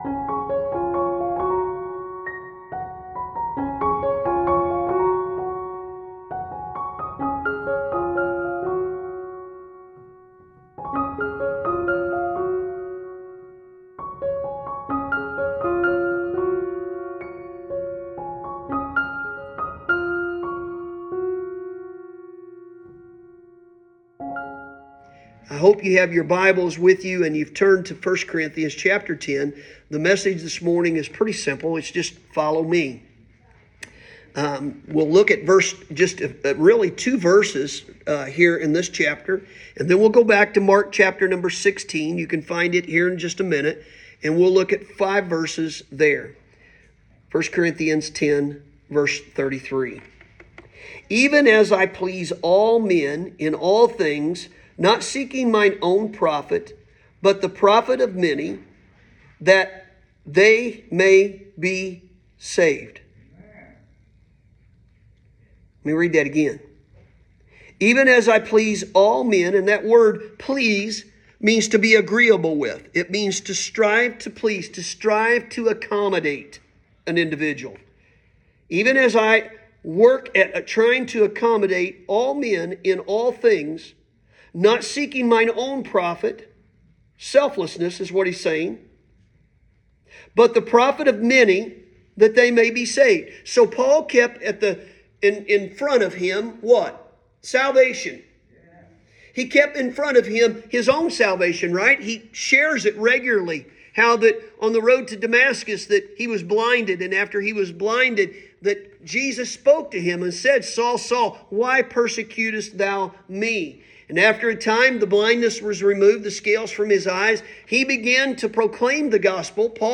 Sunday Morning – August 18th, 2024